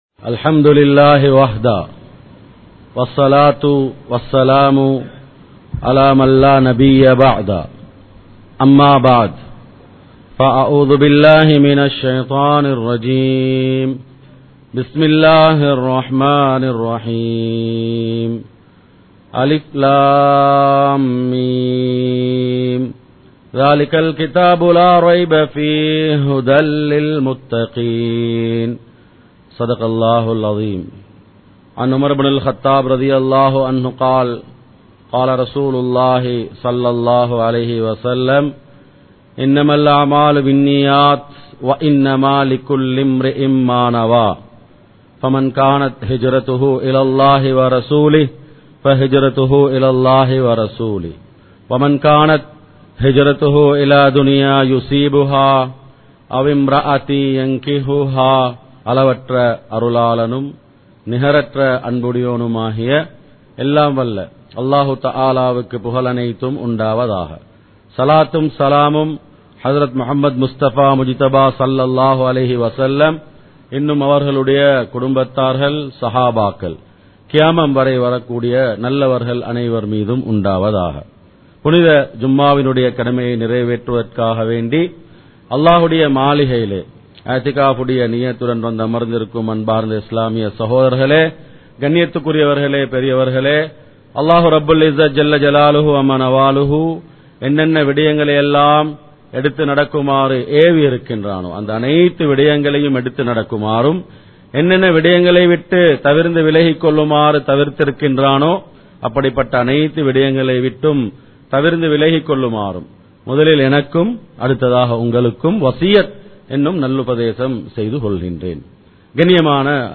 மாற்றத்திற்கான காலம் ரமழான் | Audio Bayans | All Ceylon Muslim Youth Community | Addalaichenai
Colombo 03, Kollupitty Jumua Masjith